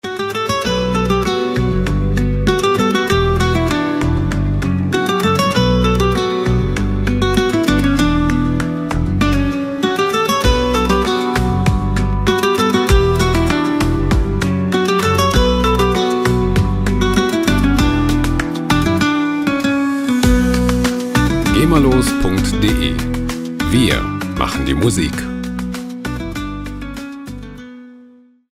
lizenzfreie Latin Musik
Musikstil: Latin Pop
Tempo: 98 bpm